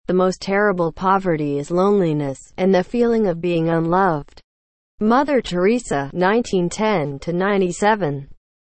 (Text-to-Speech by Sound of Text, using the engine from Google Translate)